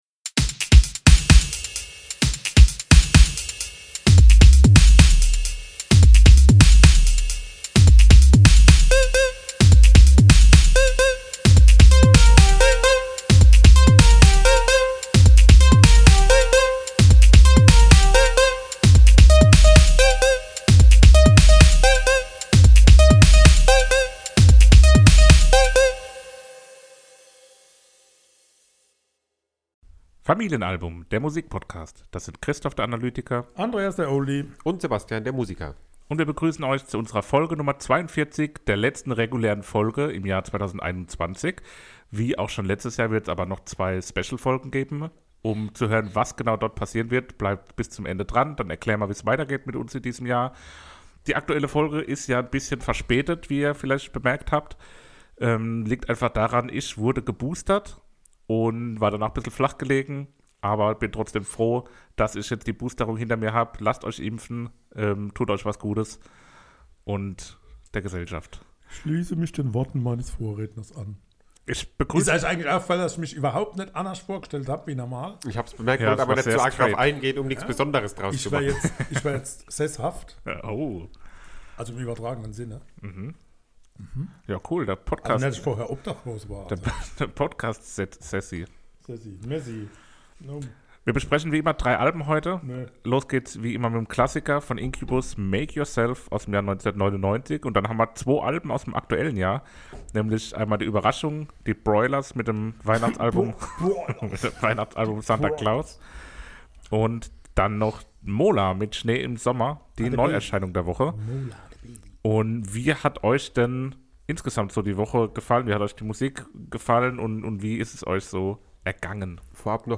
Aus Gründen, die Ihr in unserer Folge #42 erfahrt mussten wir die letzte reguläre Folge im Jahr 2021 leider um ein paar Tage verschieben, aber nun ist das wartem vorbei und Euch erwartet wieder eine gute Stunde frischer, frecher und von Frohsinn strotzender Musik-Talk der Sohn-Vater-Sohn-Kombination...